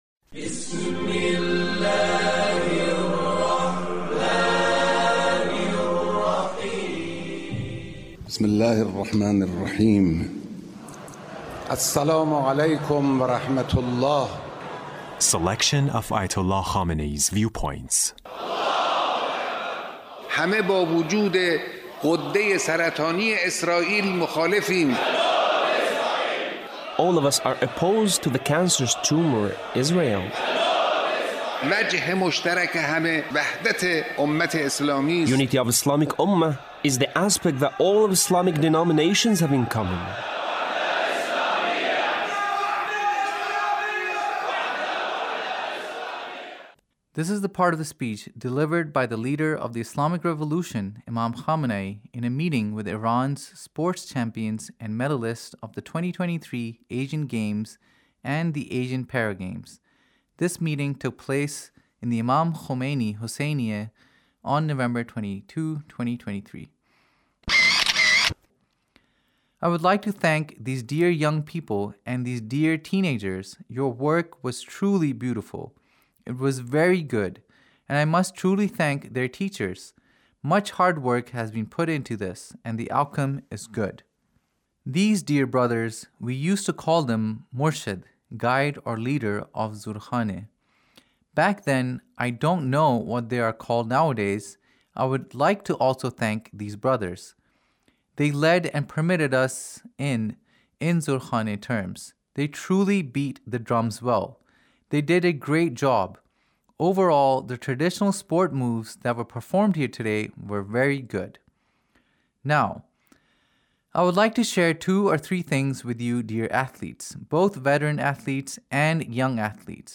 Leader's Speech in a meeting with Iran’s sports champions and medalists